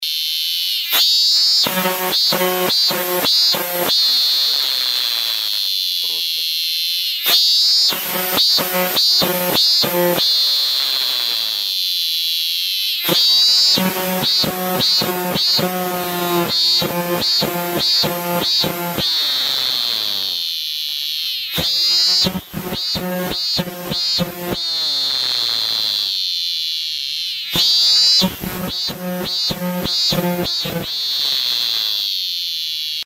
На этой странице собраны звуки цикад — от монотонного стрекотания до интенсивного хора насекомых.
Раздражающий стрекот цикады